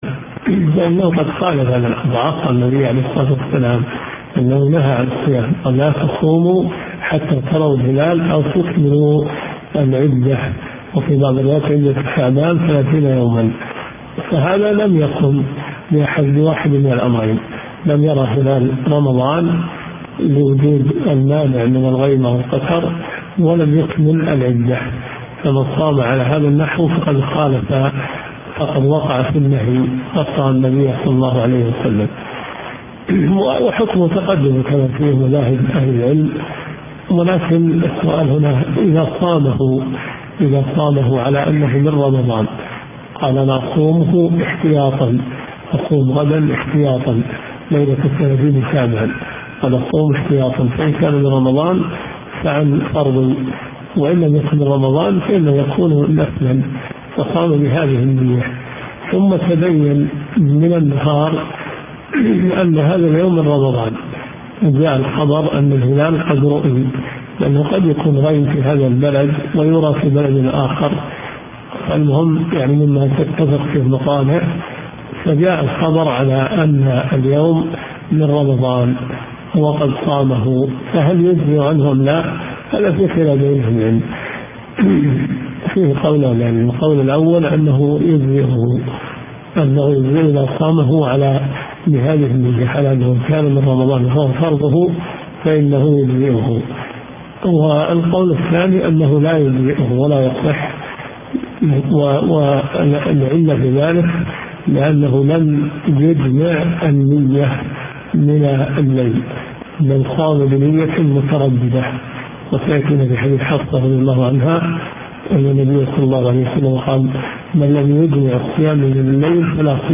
من حديث 2114 إلى حديث 2123 . دورة صيفية في مسجد معاذ بن جبل .